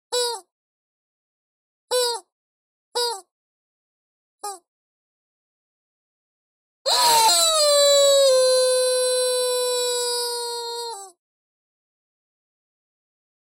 びっくりチキンは、おもちゃの「びっくりチキン」が鳴らす声です。
びっくりチキンは、おなかを押すとニワトリの鳴き声のような音を出すおもちゃです。